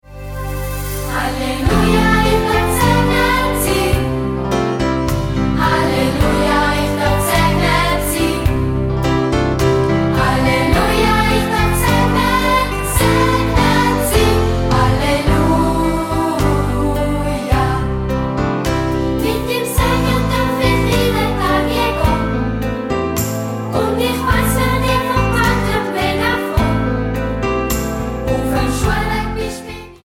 die vielen kurzen, eingängigen Refrains